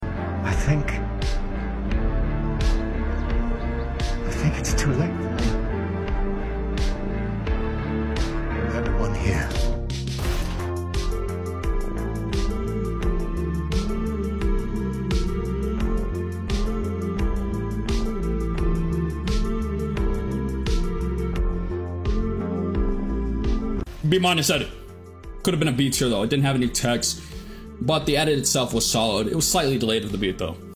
instrumental Slowed